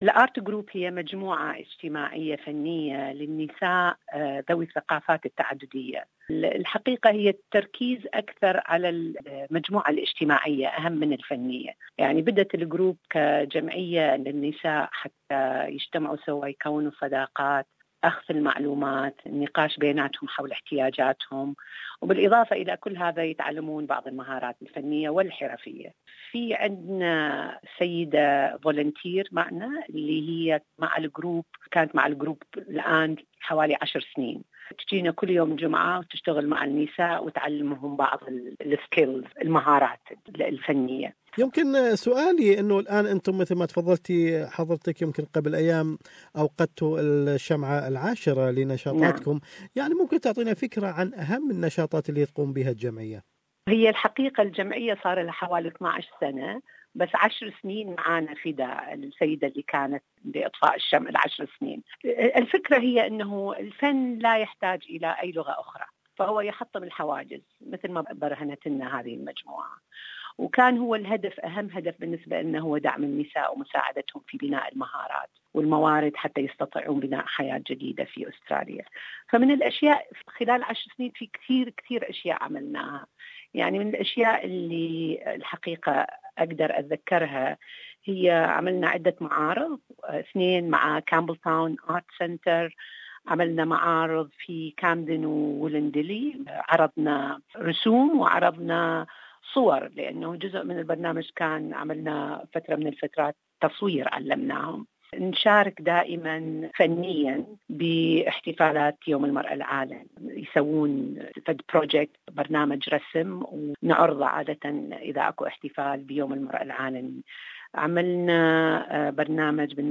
MacArthur Diversity Services center initiated a group for women to facilitate the newly arrived migrants and refugees. More is in this interview